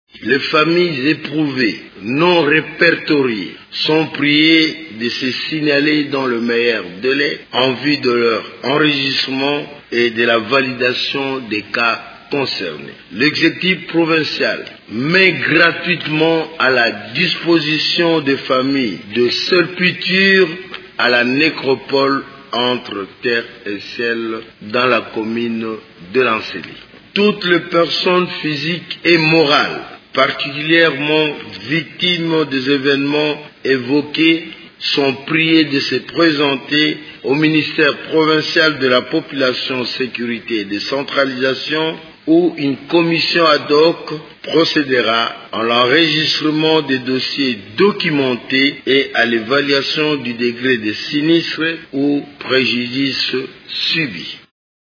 Dans cet extrait sonore, il fait état d’une cellule de crise mise en place pour enregistrer et évaluer le degré du sinistre subi par les personnes morales ou physiques lors des violences du 19 et 20 septembre à Kinshasa: